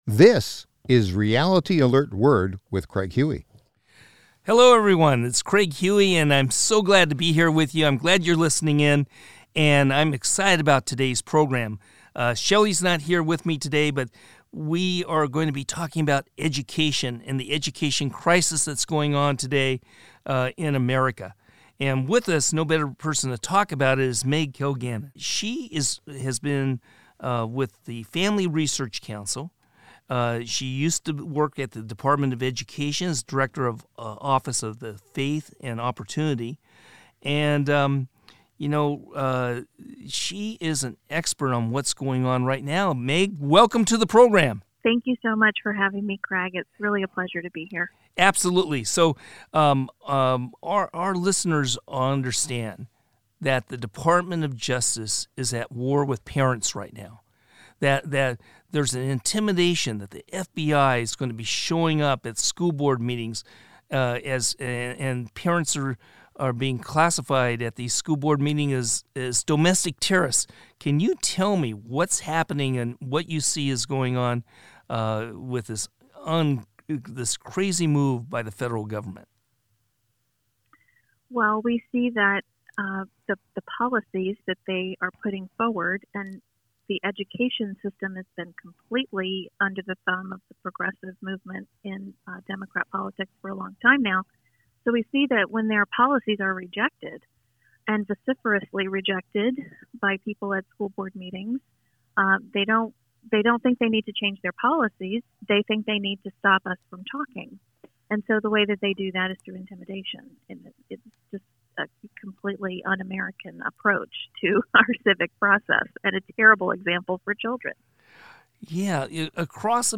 This powerful interview is 13-minutes long and you will get a full picture of what is happening in our public/government schools today.